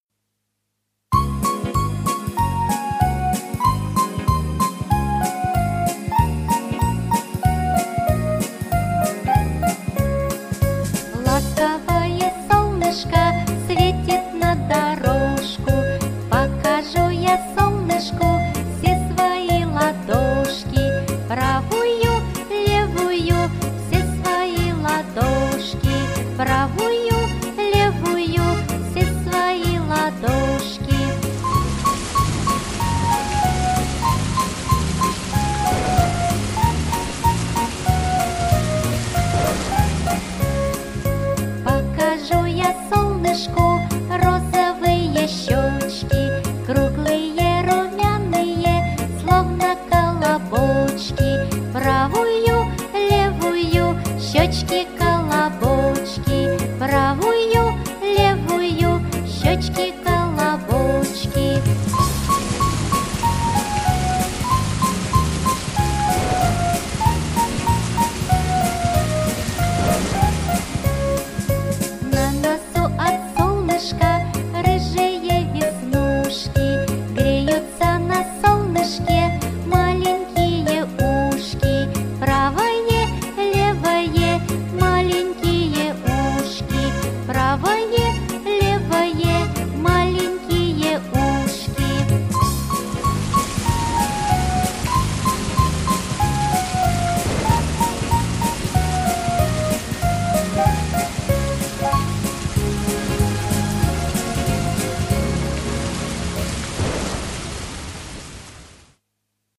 Я и солнышко - песенка с движениями - слушать онлайн